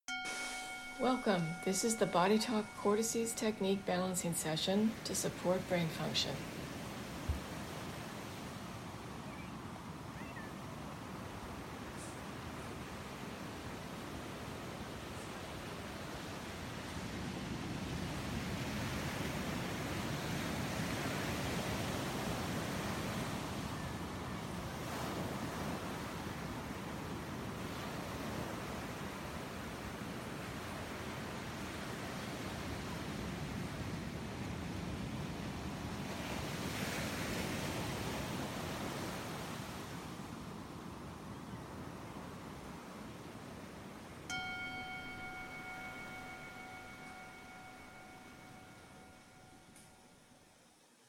You may close your eyes and take some relaxing, deep breaths while this eBal™ plays. A note about the sound: The recorded Cortices Technique session is playing silently. We added the sound of the ocean in the background and bells at the start and end.